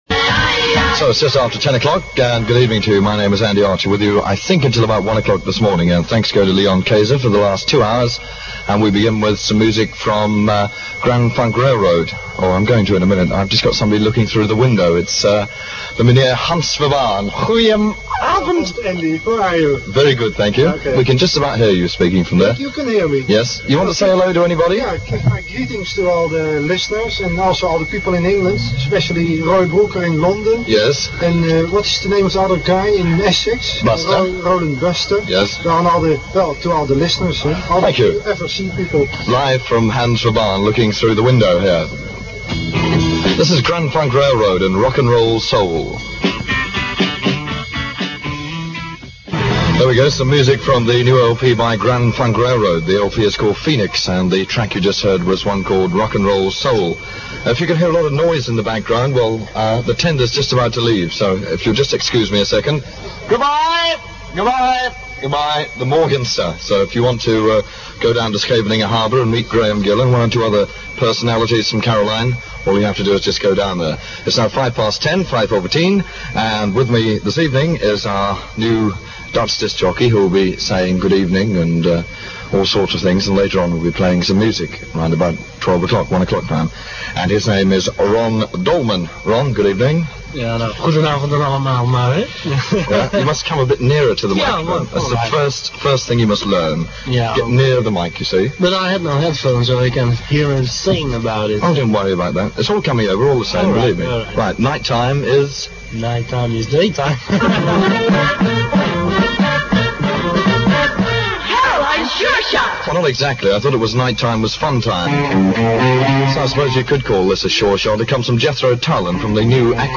Edited from a recording posted on The Offshore Radio Club Forum (duration 2 minutes 48 seconds)